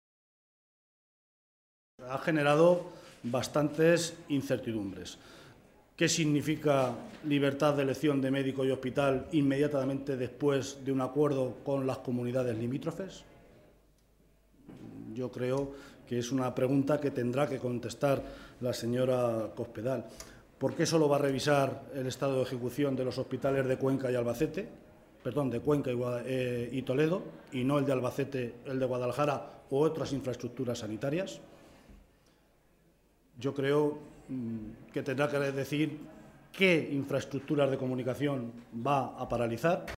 José Luis Martínez Guijarro, portavoz del Grupo Parlamentario Socialista en las Cortes de Castilla-La Mancha
Cortes de audio de la rueda de prensa